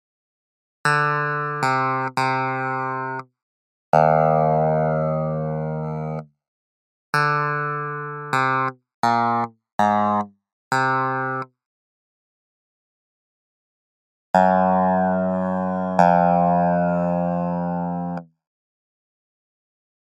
Key written in: F Major
Each recording below is single part only.
a reed organ